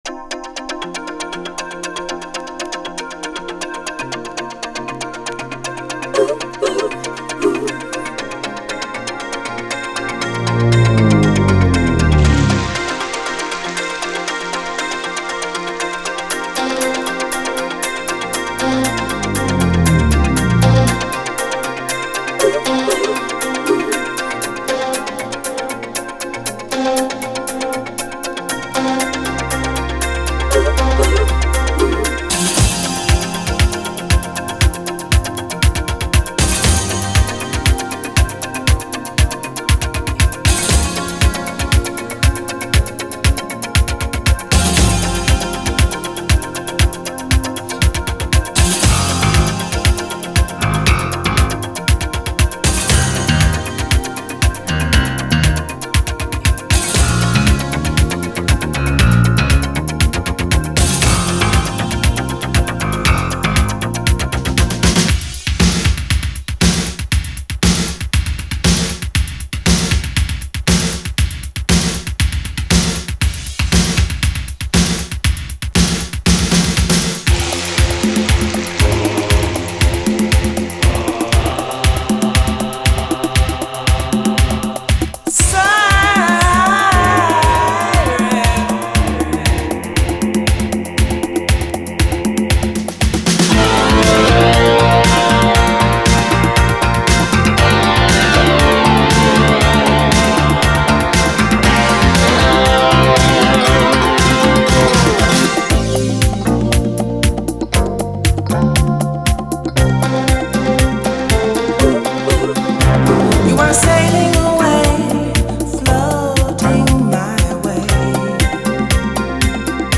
DISCO
幻想的なシンセのイントロがカッコいいイタロ・ディスコ！
黒人女性シンガーのイタロ・ディスコ！